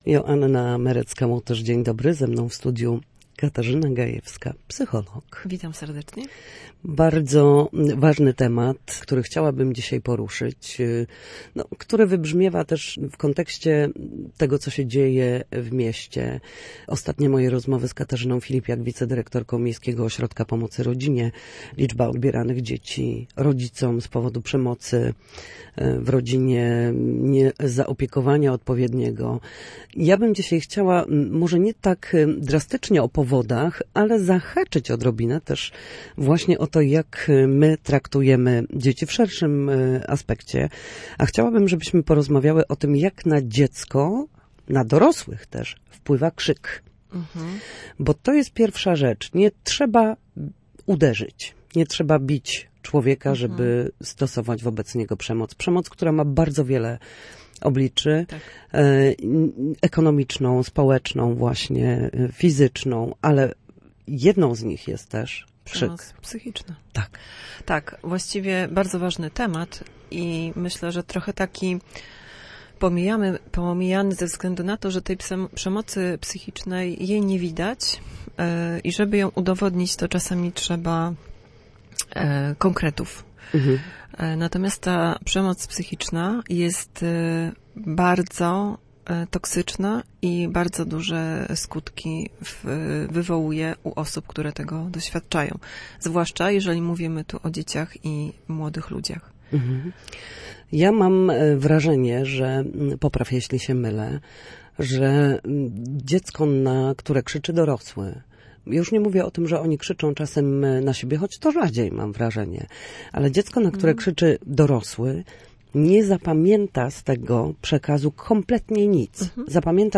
W każdą środę, w popołudniowym Studiu Słupsk Radia Gdańsk, rozmawiamy o tym, jak wrócić do formy po chorobach i urazach.